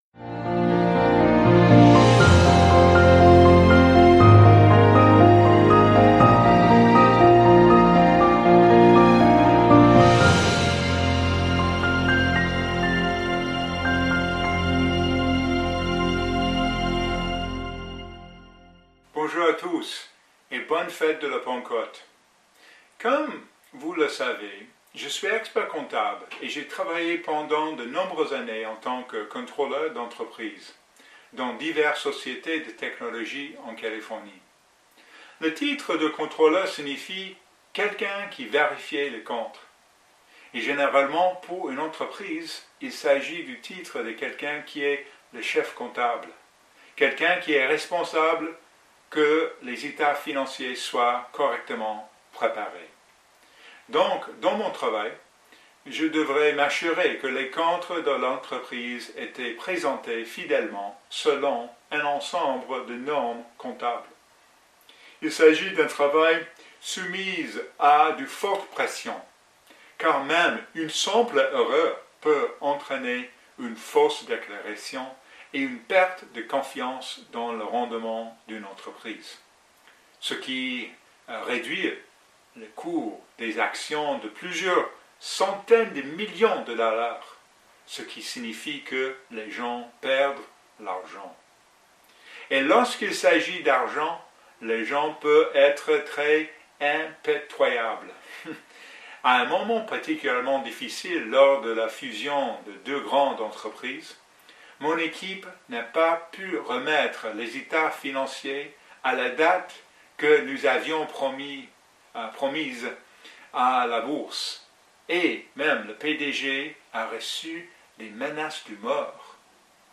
Dans ce sermon, préparé pour la fête de la Pentecôte